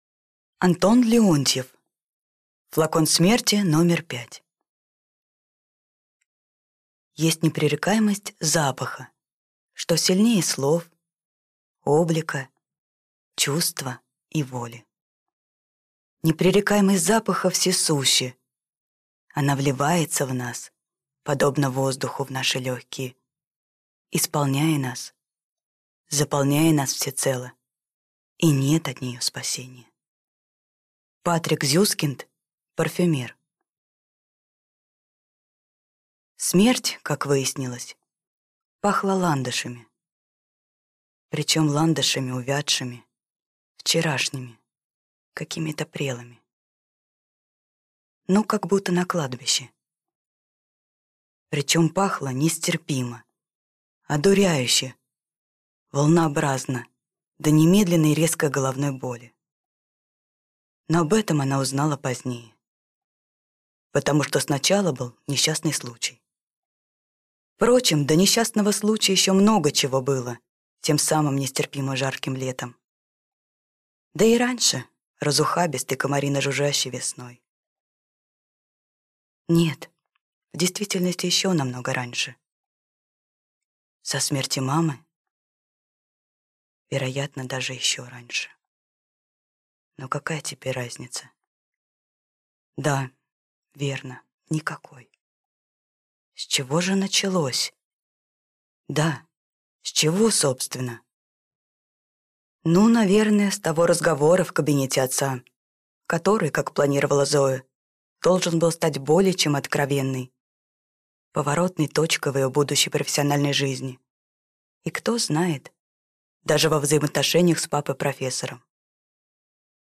Аудиокнига Флакон смерти №5 | Библиотека аудиокниг